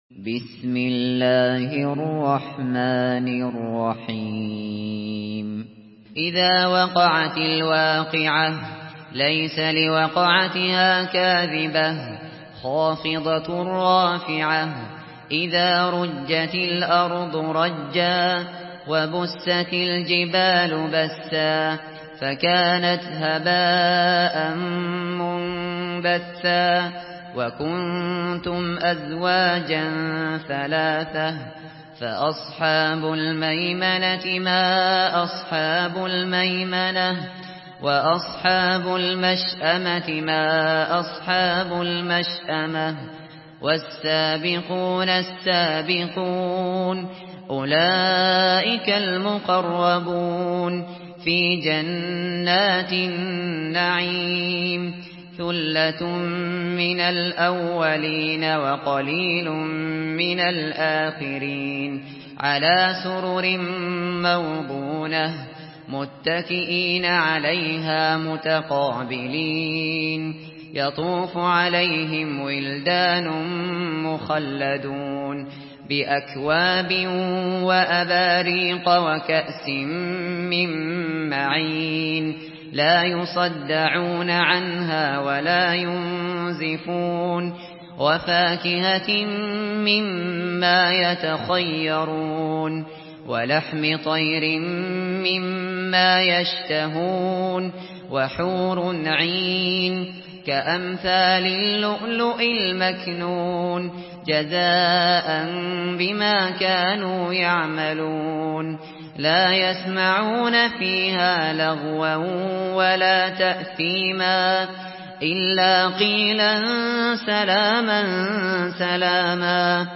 سورة الواقعة MP3 بصوت أبو بكر الشاطري برواية حفص
مرتل